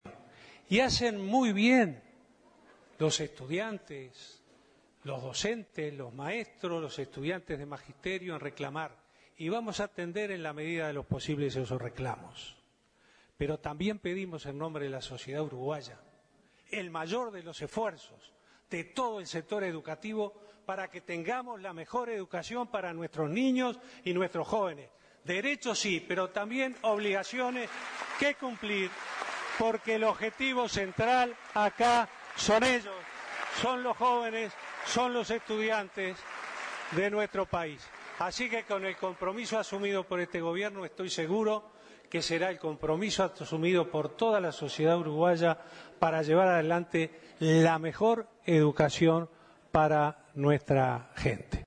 Se realizo el tercer Consejo de Ministros público en Dolores, Soriano.